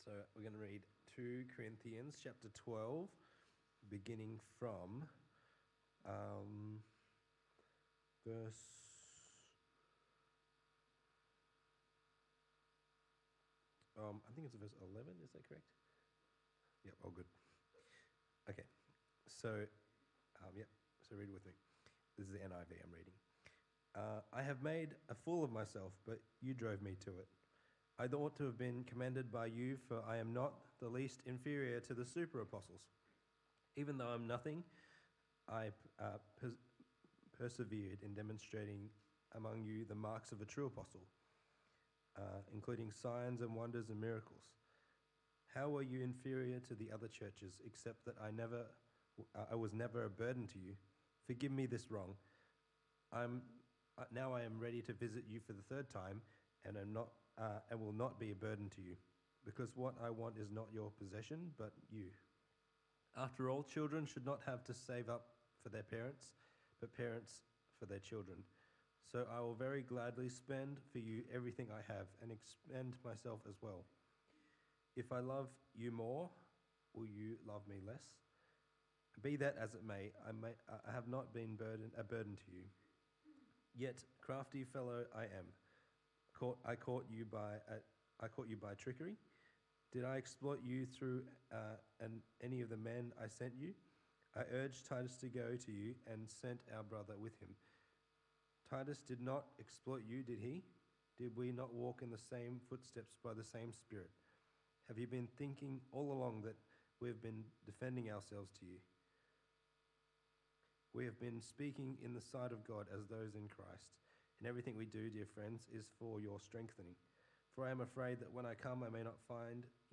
Passage: 2 Corinthians 12:11-13:14 Talk Type: Bible Talk